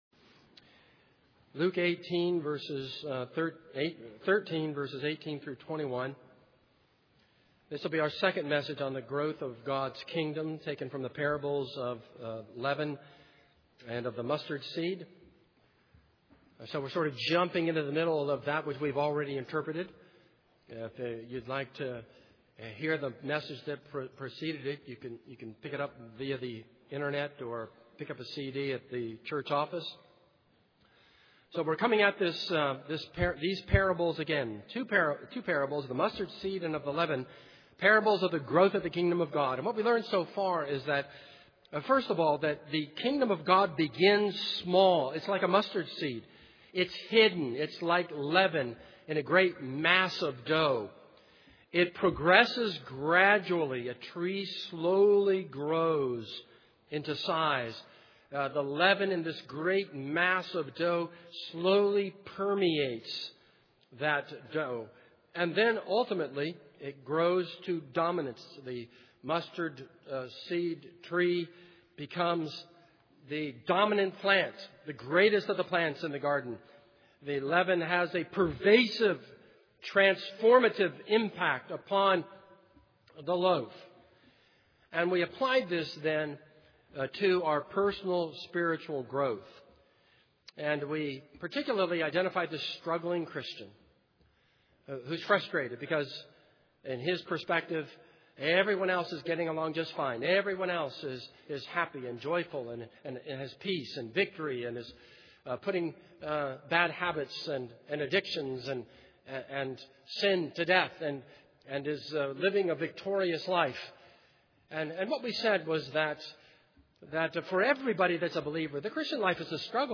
This is a sermon on Luke 13:18-21.